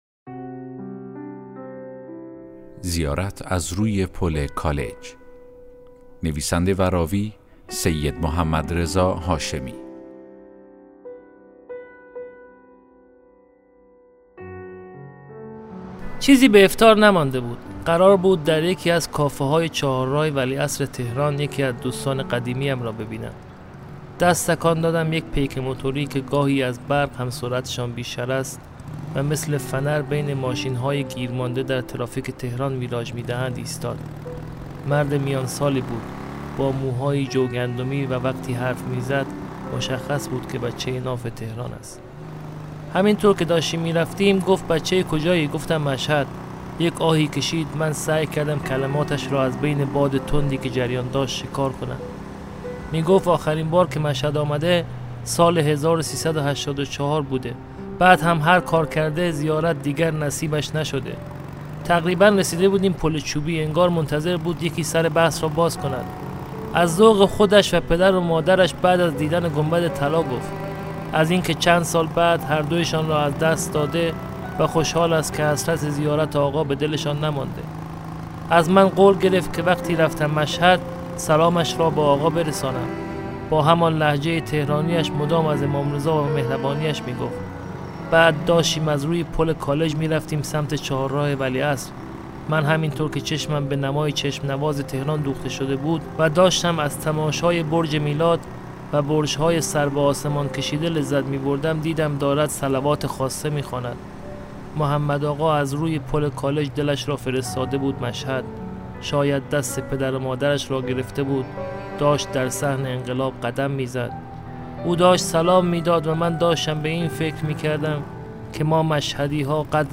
داستان صوتی: زیارت از  روی پل کالج